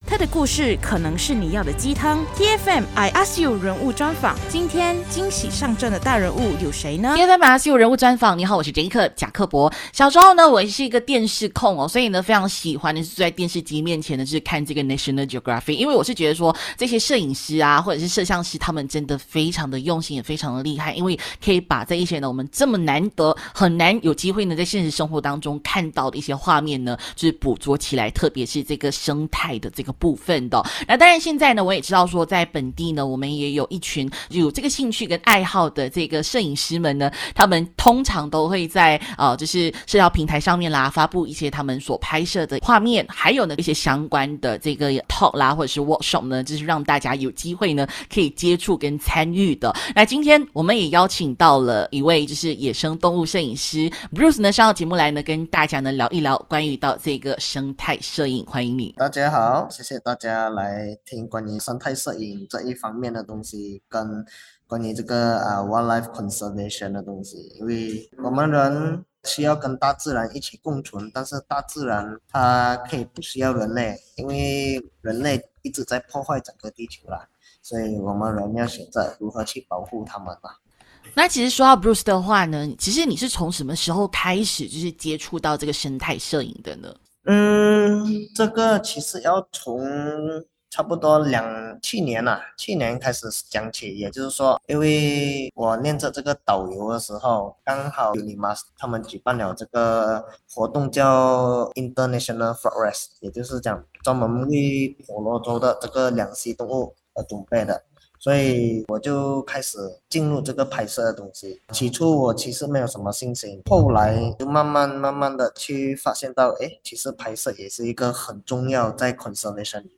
人物专访 自然生态摄影师